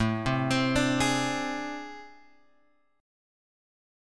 AmM11 chord {5 3 x 5 3 4} chord